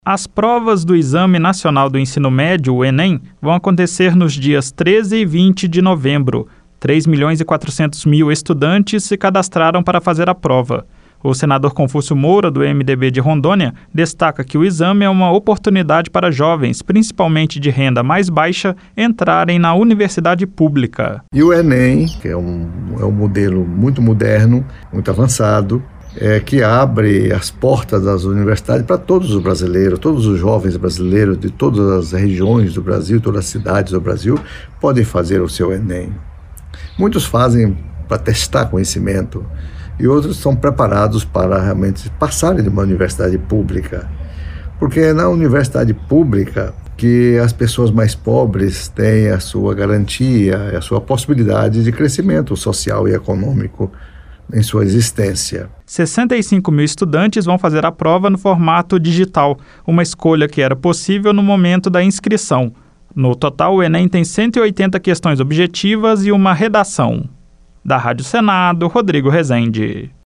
3 milhões e 400 mil estudantes vão fazer o ENEM, Exame Nacional do Ensino Médio, nos próximos dias 13 e 20 de novembro. O senador Confúcio Moura ressalta que a prova é uma oportunidade para o ingresso em universidades públicas.